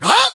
tnt_guy_hurt_01.ogg